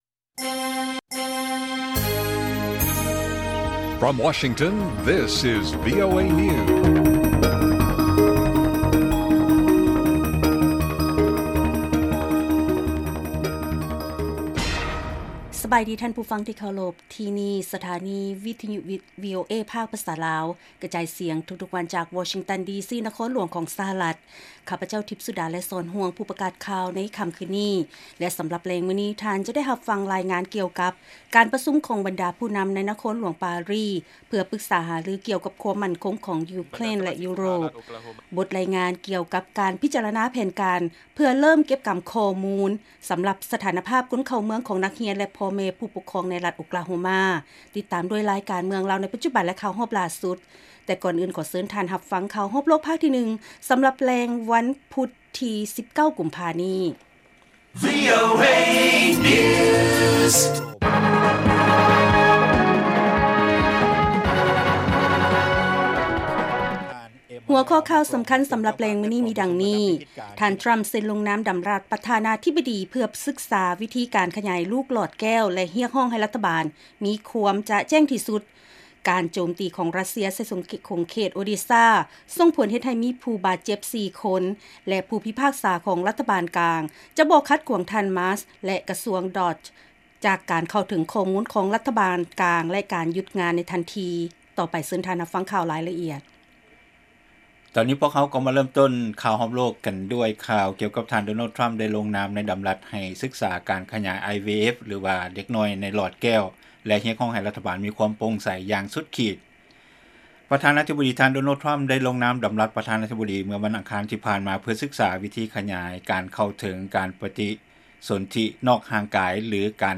ລາຍການກະຈາຍສຽງຂອງວີໂອເອ ລາວ: ທ່ານ ດໍໂນລ ທຣໍາ ໄດ້ລົງນາມໃນດຳລັດ ໃຫ້ສຶກສາການຂະຫຍາຍ IVF ແລະ ຮຽກຮ້ອງໃຫ້ ລັດຖະບານມີຄວາມໂປ່ງໃສຢ່າງສຸດຂີດ